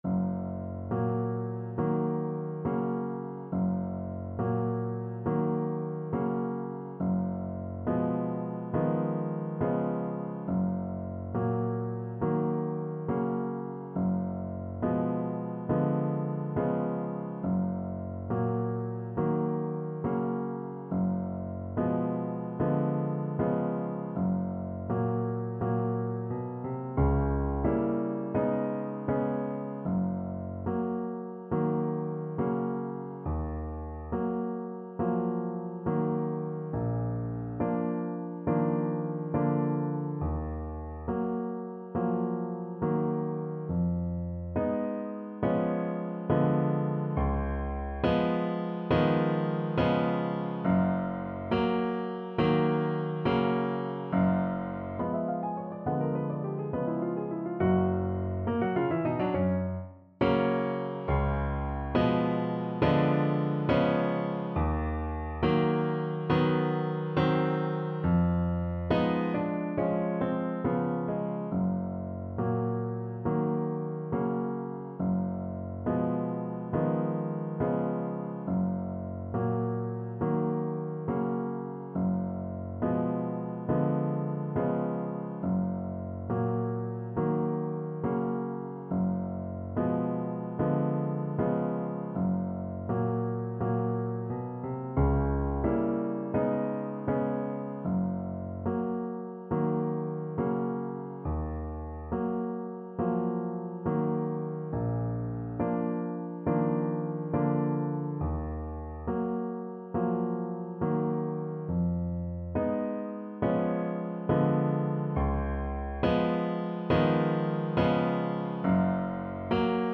Play (or use space bar on your keyboard) Pause Music Playalong - Piano Accompaniment Playalong Band Accompaniment not yet available reset tempo print settings full screen
Andante non troppo con grazia =69
Gb major (Sounding Pitch) Eb major (Alto Saxophone in Eb) (View more Gb major Music for Saxophone )
Classical (View more Classical Saxophone Music)